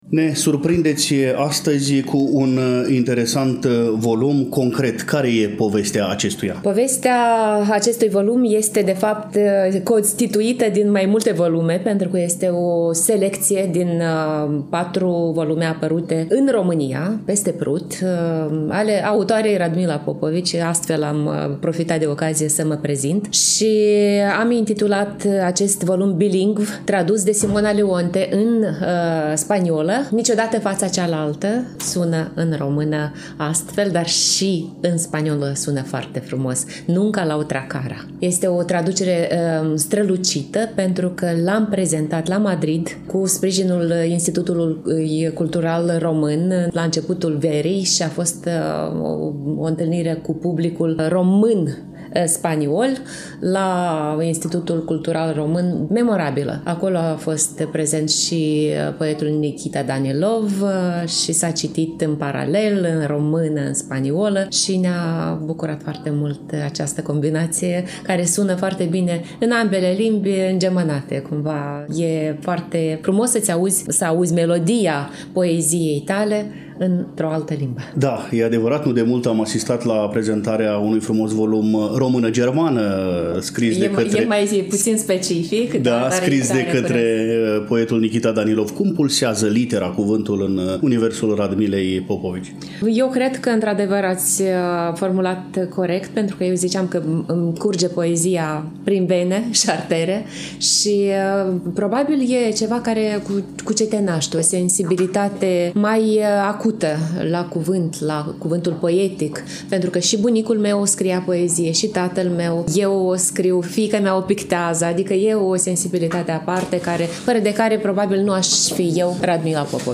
difuzăm un scurt dialog cu poeta